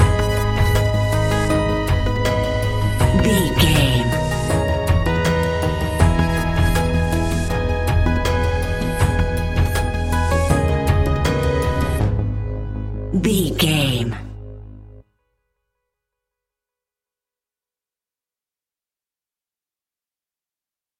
Aeolian/Minor
G#
ominous
dark
eerie
electric piano
percussion
drums
synthesiser
strings
horror music